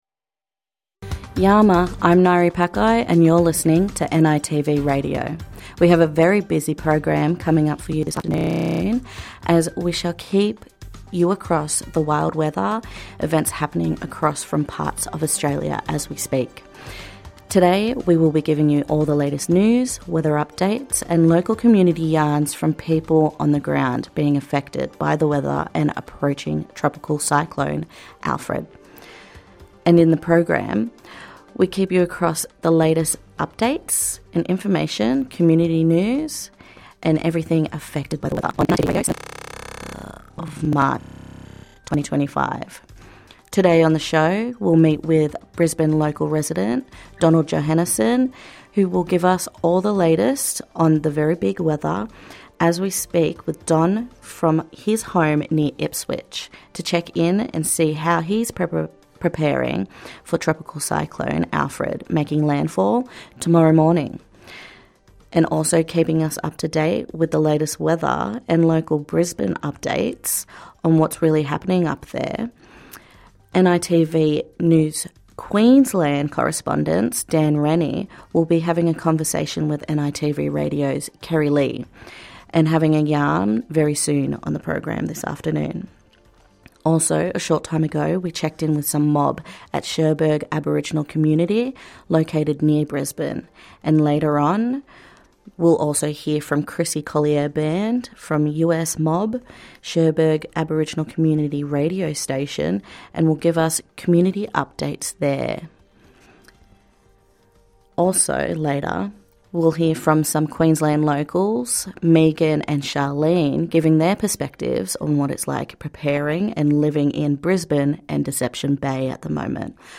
NITV Radio News, Politics and Weather Updates